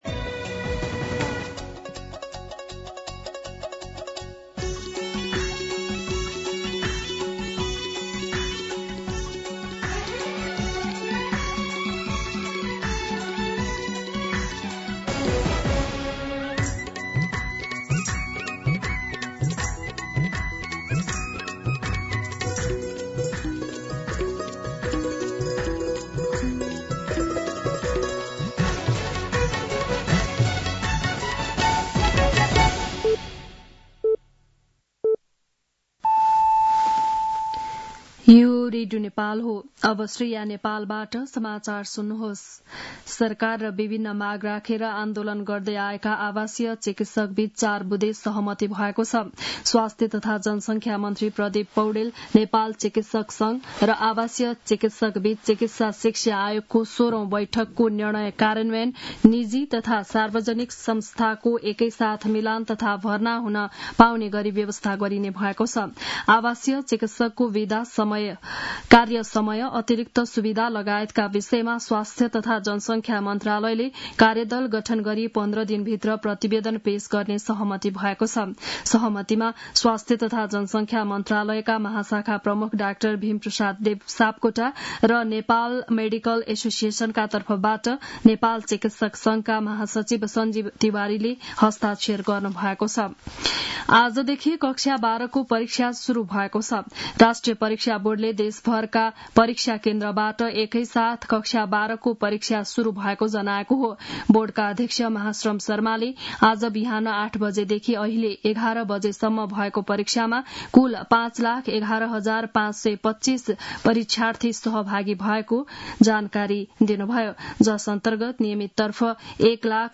बिहान ११ बजेको नेपाली समाचार : २२ वैशाख , २०८२
11-am-Nepali-News.mp3